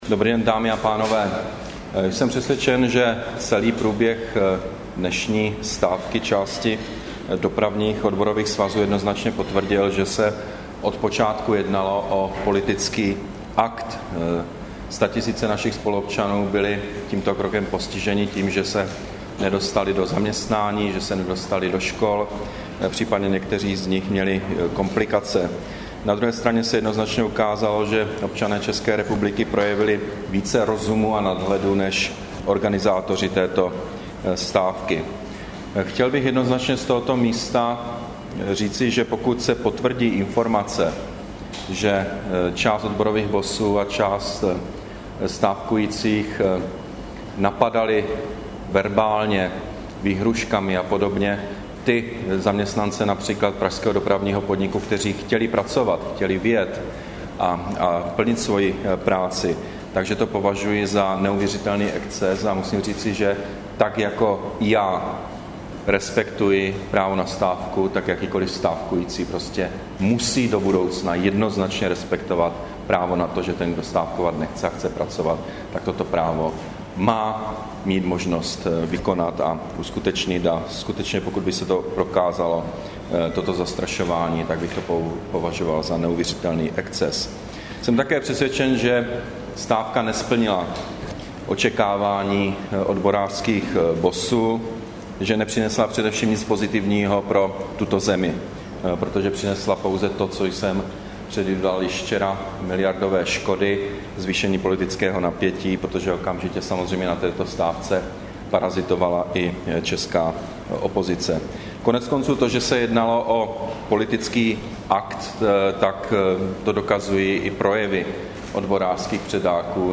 Tisková konference premiéra Petra Nečase ke stávce, 16. června 2011